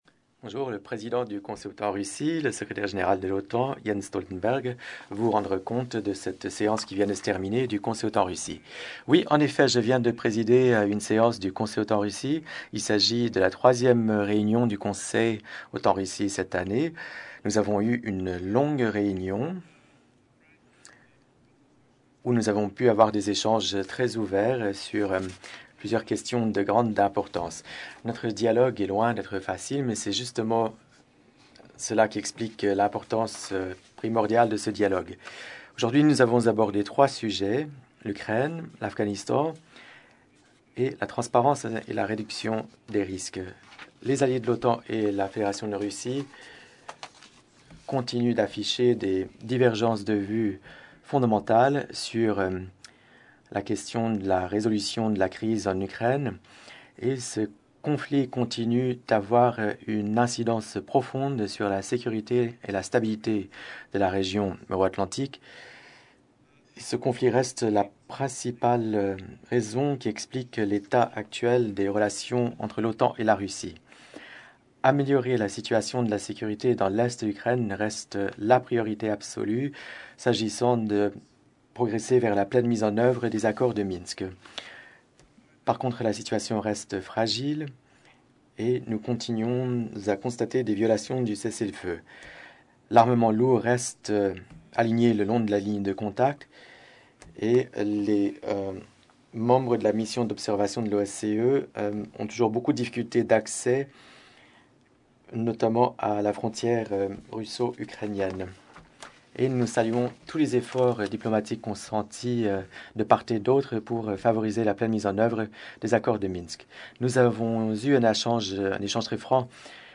Press point
by the NATO Secretary General Jens Stoltenberg following the meeting of the NATO-Russia Council